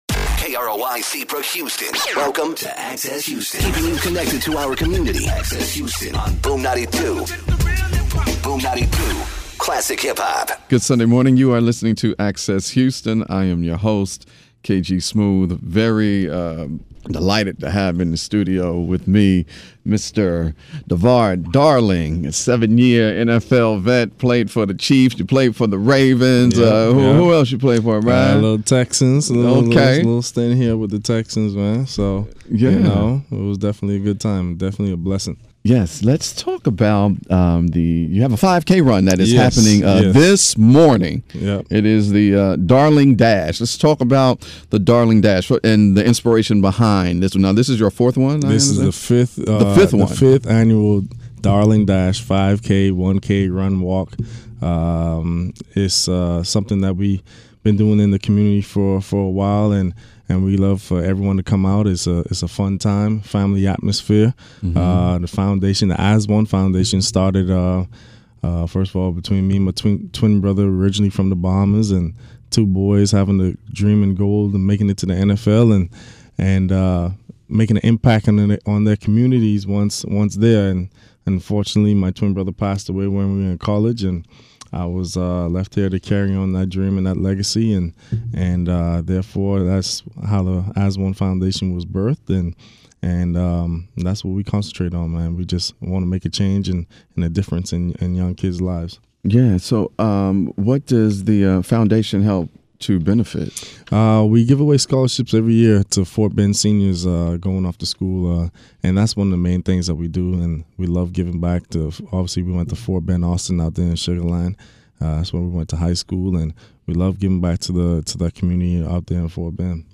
Learn about his foundation and the importance of staying hydrated. And of course the fellas talk football. Take a listen to the interview above.